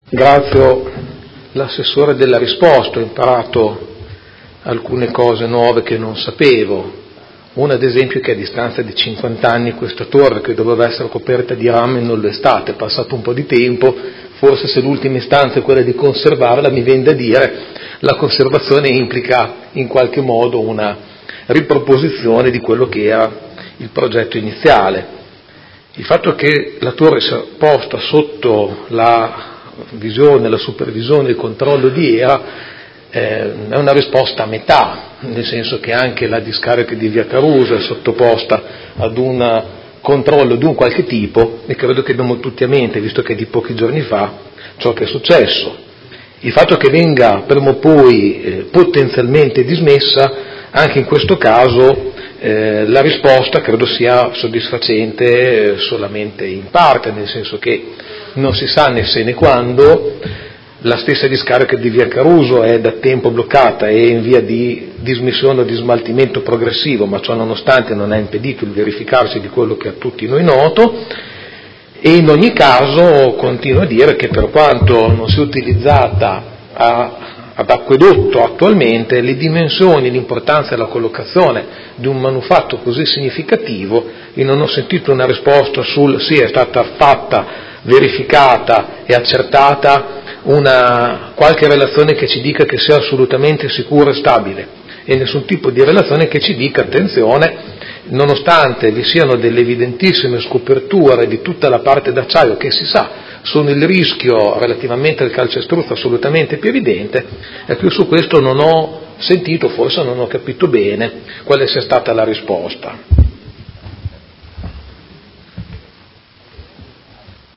Seduta del 7/03/2019 Interrogazione del Consigliere Pellacani (Energie per l’Italia) avente per oggetto: La torre dell’acquedotto del Parco della Resistenza è sicura?